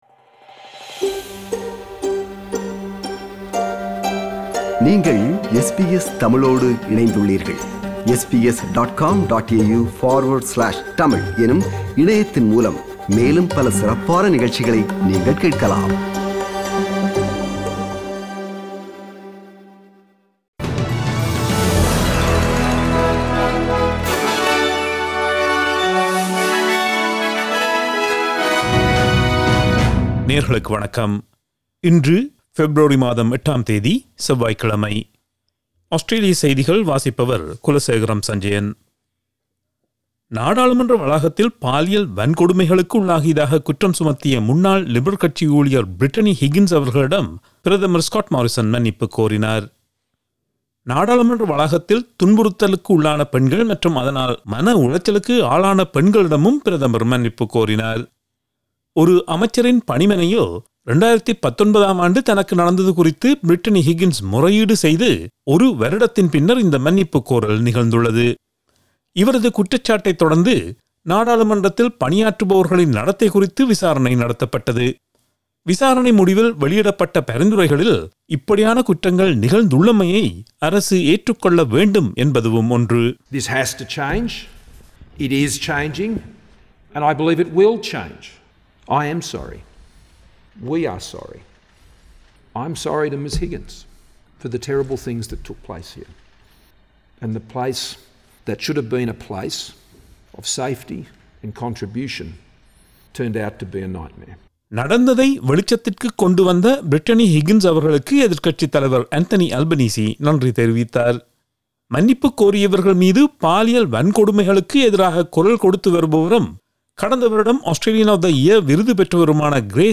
Australian news bulletin for Tuesday 08 February 2022.